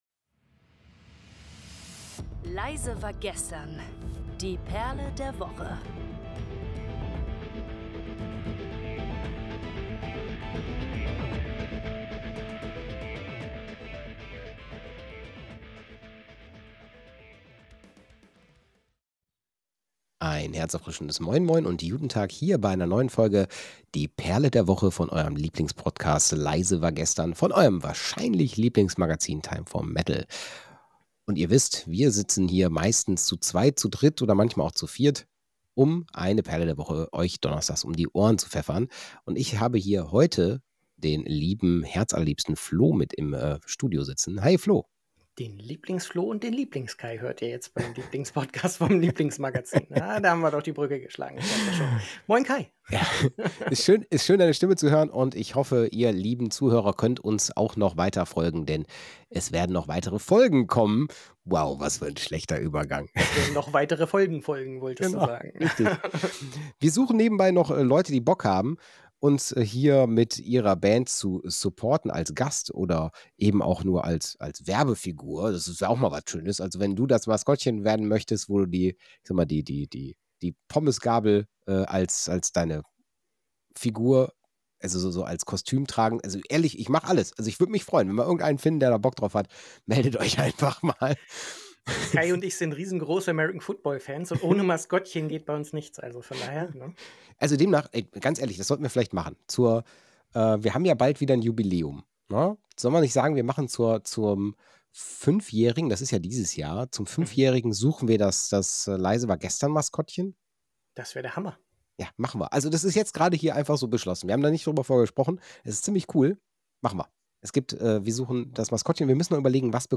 Mit Hilfe des Zufallsgenerators wird Detroit zur Perle der Woche, und die Hosts diskutieren, warum die Stadt mehr als nur Motown kann.